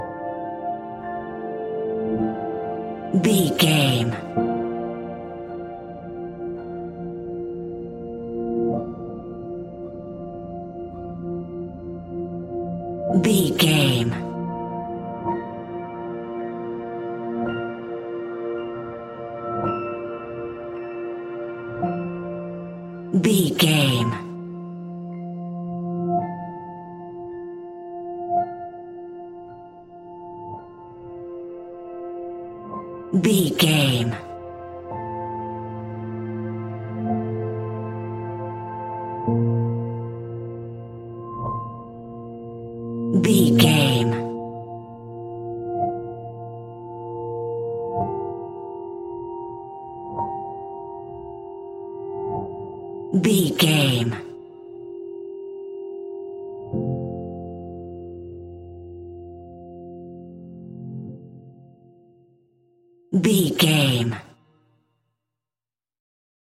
Ionian/Major
laid back
new age
chilled electronica
ambient